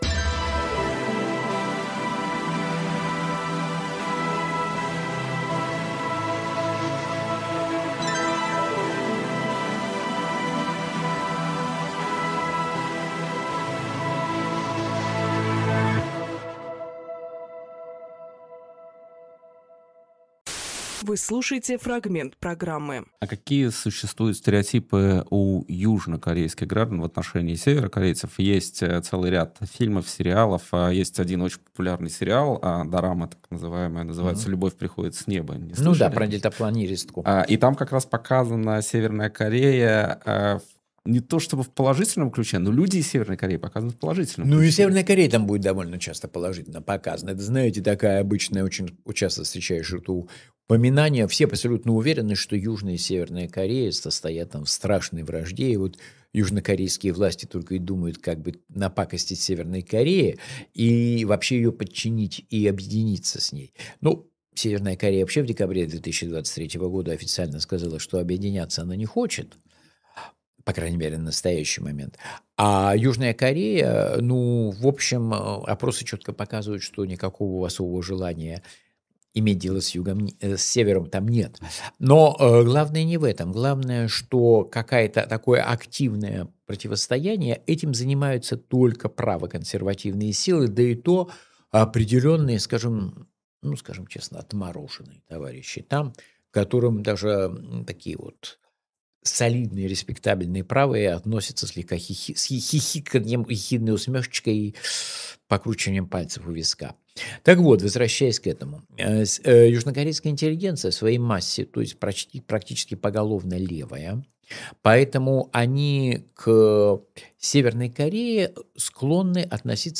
Андрей Ланьковкореевед, профессор университета Кунмин
Фрагмент эфира от 24.02.26